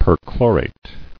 [per·chlo·rate]